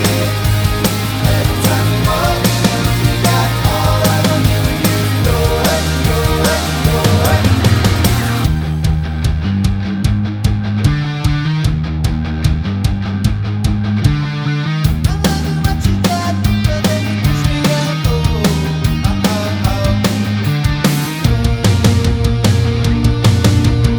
no Backing Vocals Pop (2010s) 2:50 Buy £1.50